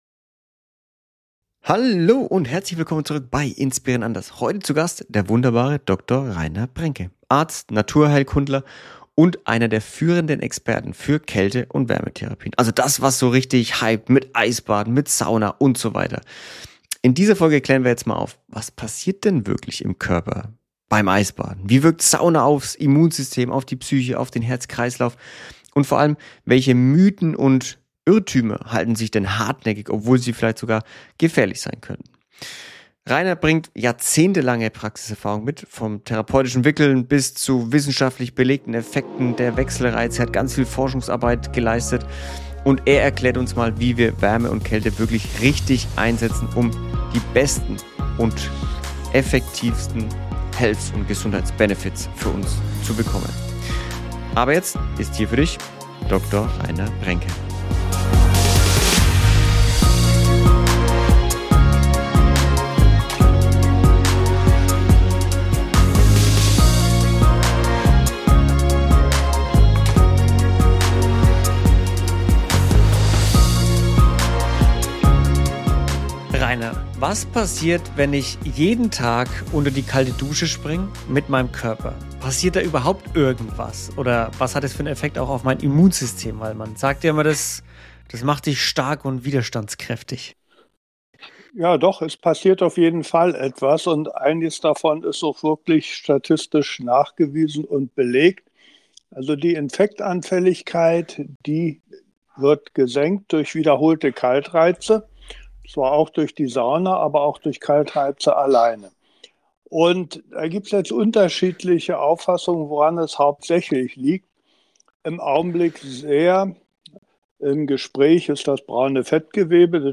Ein Gespräch, das Lust macht, die eigene Gesundheitsroutine zu hinterfragen – und einfache, natürliche Reize wieder ernst zu nehmen.